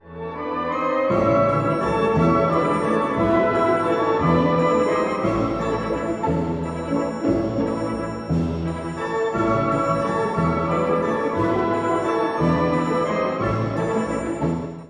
オーケストラ曲の紹介
チャイコフスキーの他の作品と比べて明るい雰囲気をもっています。
陽気な民謡旋律が、万華鏡のように展開します！